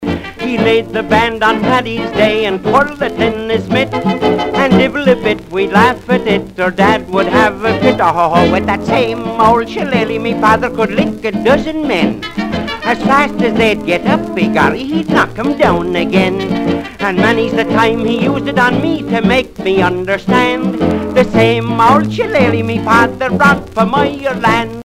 vocal refrain